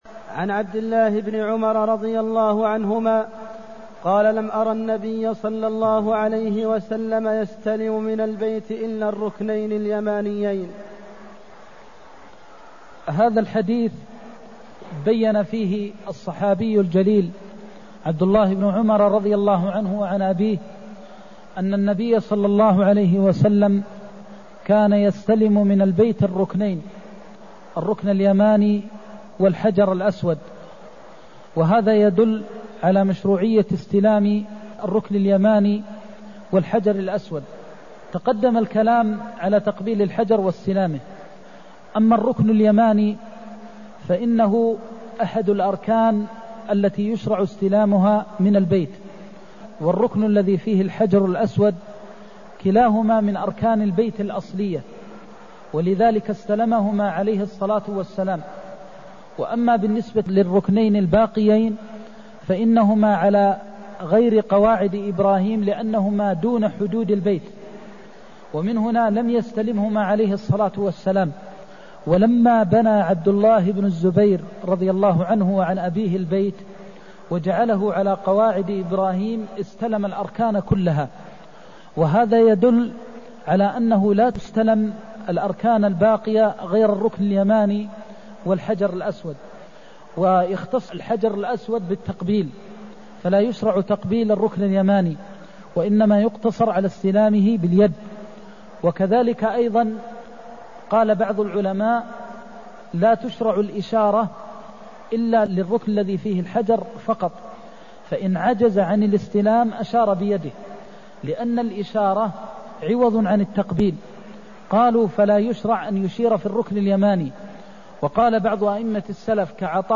المكان: المسجد النبوي الشيخ: فضيلة الشيخ د. محمد بن محمد المختار فضيلة الشيخ د. محمد بن محمد المختار لم أر النبي يستلم من البيت إلا الركنين اليمانيين (219)